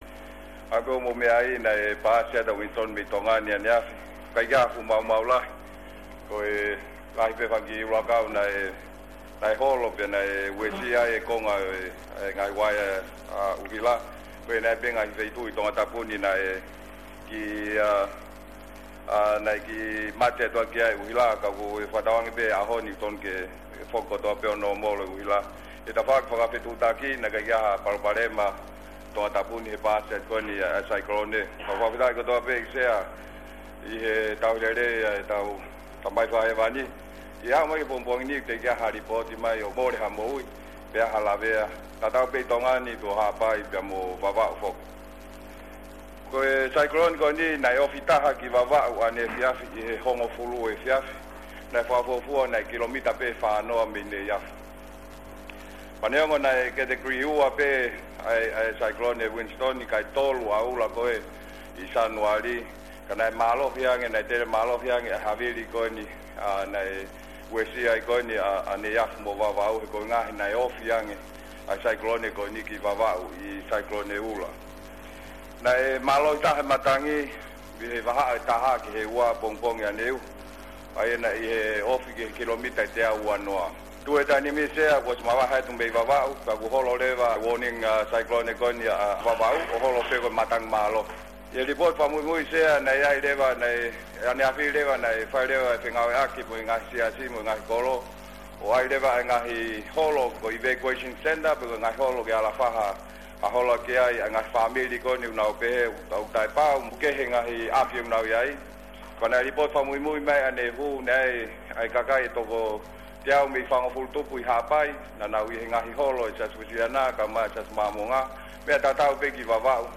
Briefing by the deputy Prime Minister for Tonga Siaosi Sovaleni in regards to the damages and information on Cyclone Winston in Parliament